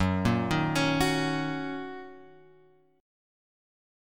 F#6 chord {2 1 1 x 2 2} chord